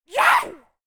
femalezombie_spotted_05.ogg